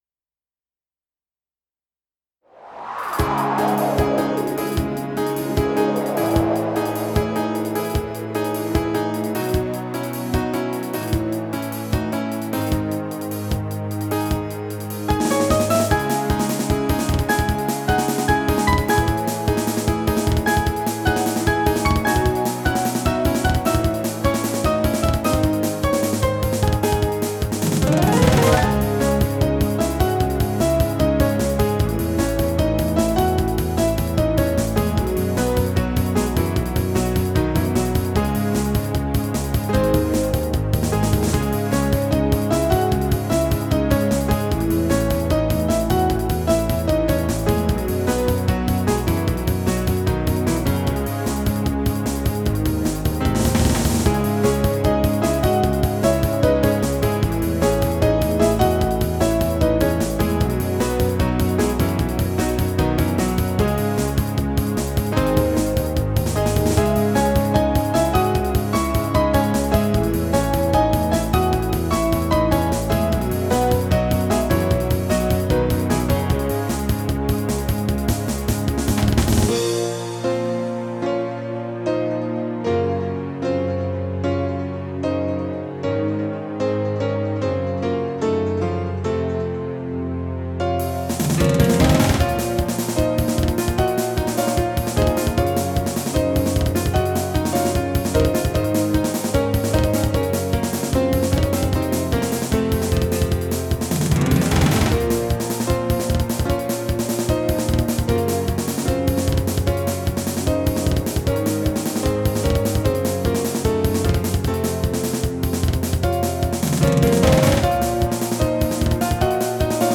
由Roland Sound Canvas 88Pro实机录制；MP3采样率44.1KHz，码率192Kbps。